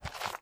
STEPS Dirt, Walk 23.wav